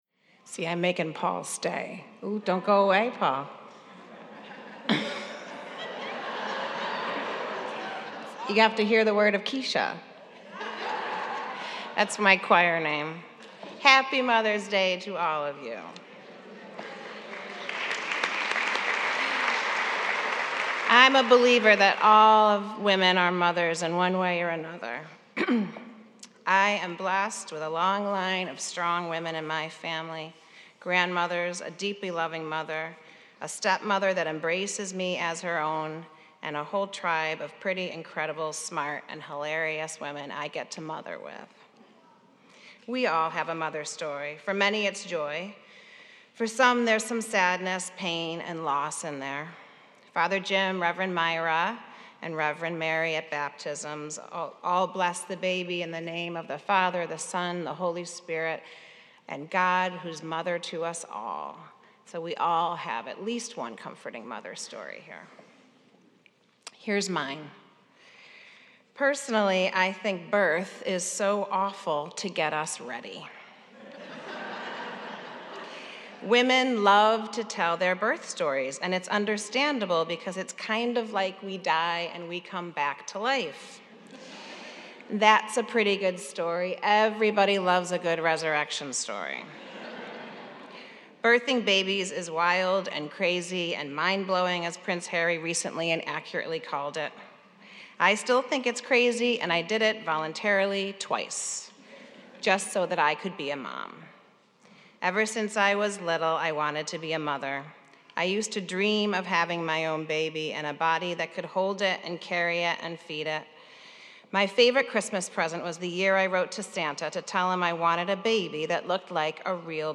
Mother’s Day Homily